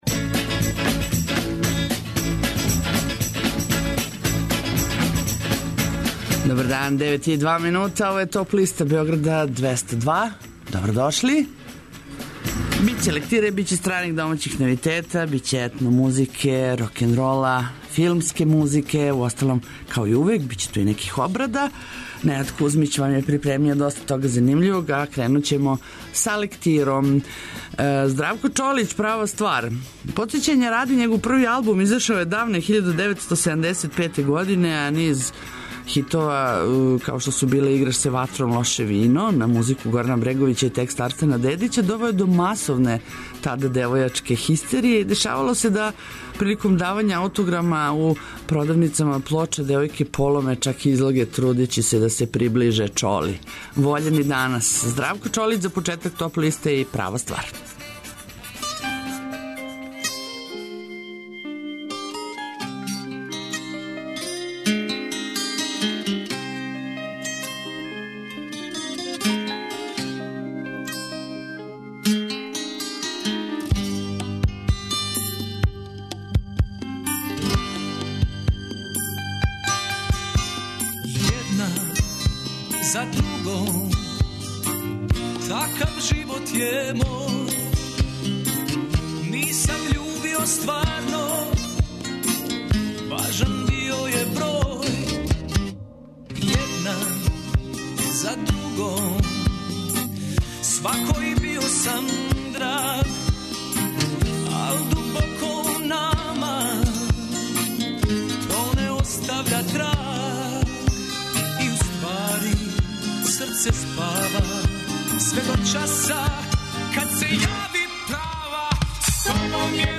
Све то и још много добре музике моћи ћете да чујете у новој Топ листи 202, у првој седмици новембра, сваког радног дана између 9 и 10 сати.